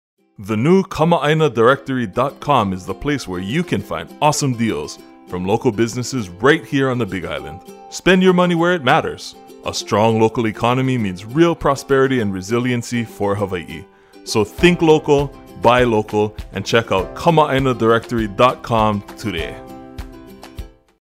Kama’āina Directory – Radio Spots